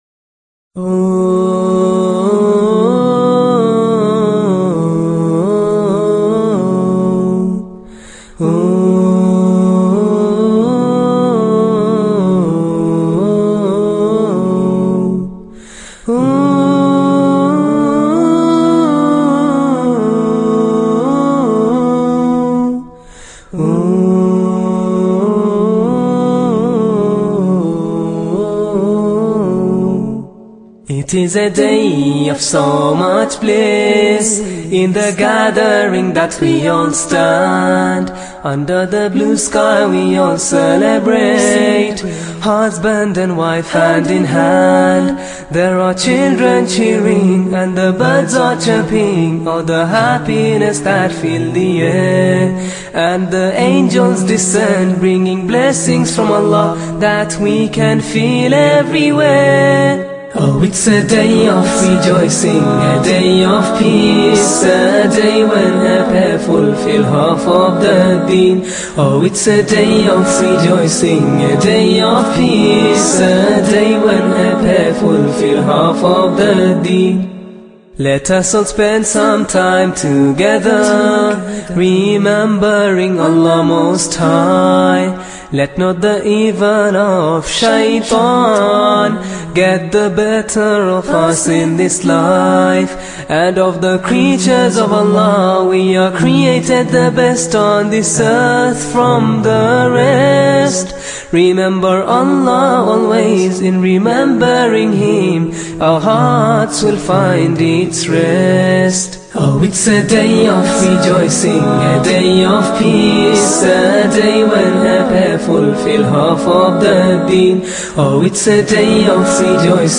Marriage in Islam is described as completing half of your faith, and this beautiful nasheed captures the joy, gratitude, and spiritual significance of the bond between husband and wife.